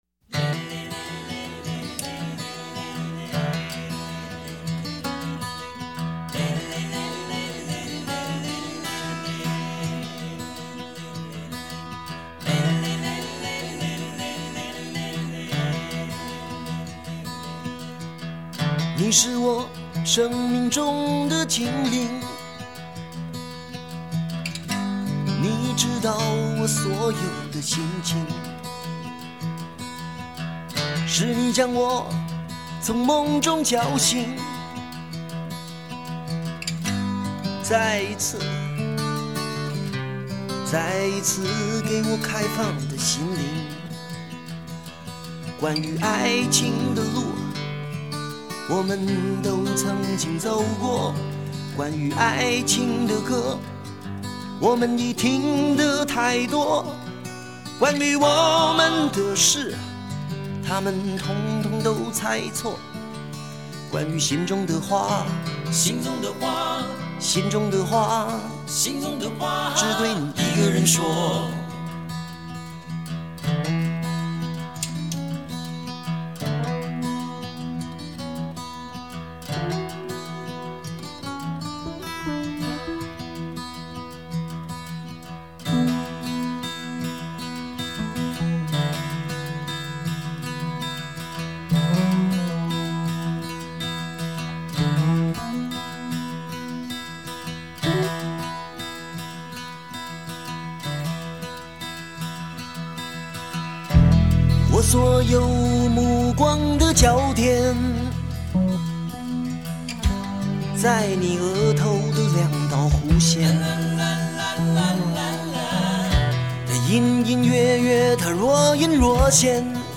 整张专辑的编曲，以木吉他为基调，偶尔缀以简约的乐团伴奏，凝炼、集中、张力十足的音场，是台湾流行音乐前所未闻的创举。